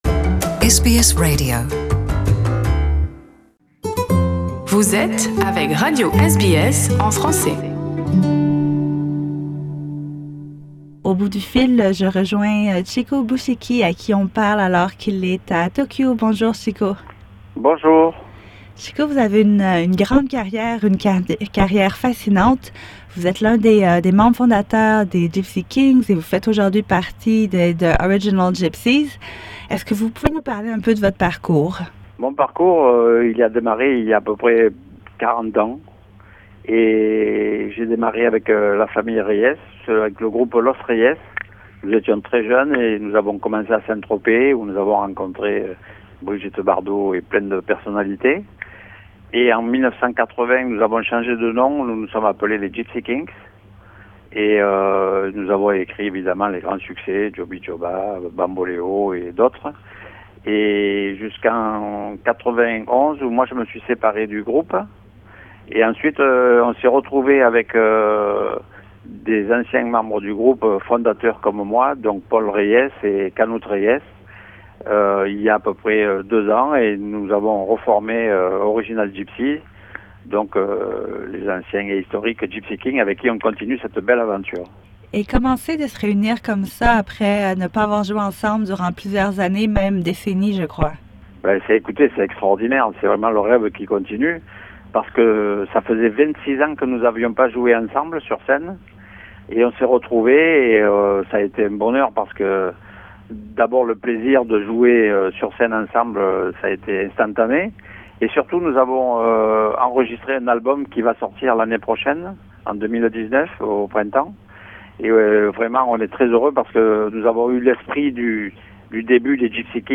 Interview avec l’un des membres du groupe, Chico Bouchikhi.